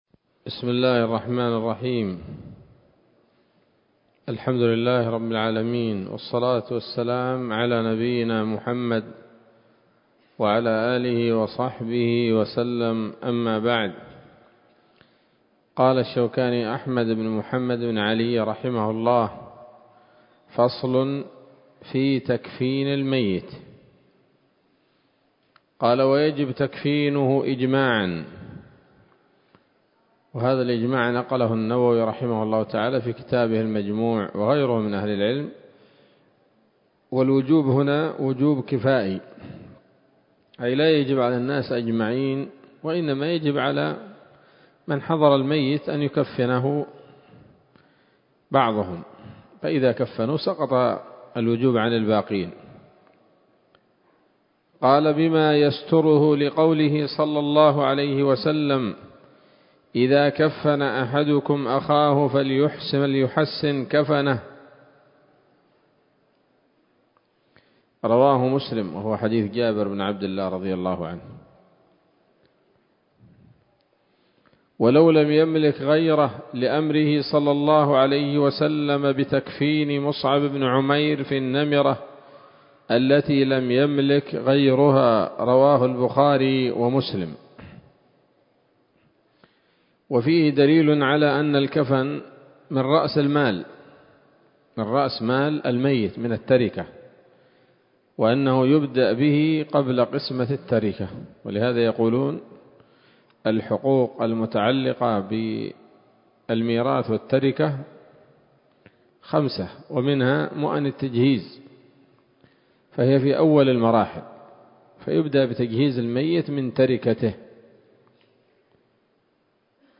الدرس الثالث من كتاب الجنائز من السموط الذهبية الحاوية للدرر البهية